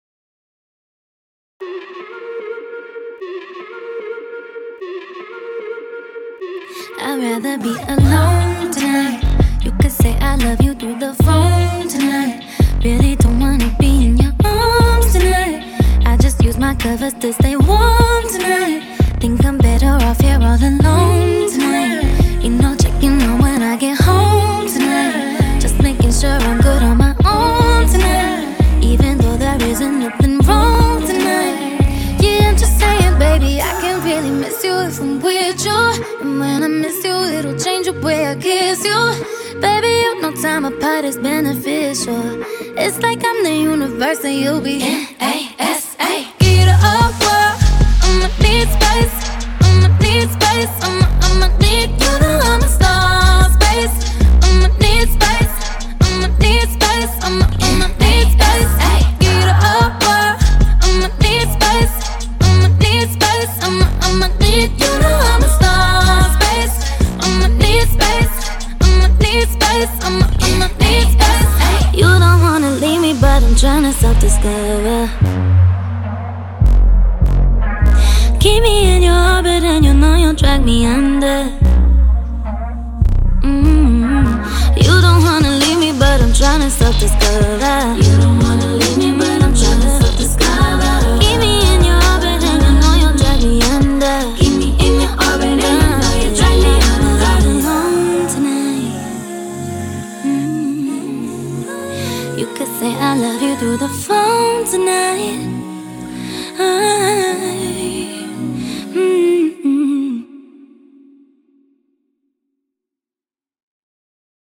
BPM75
Song runs fully under 80 bpm.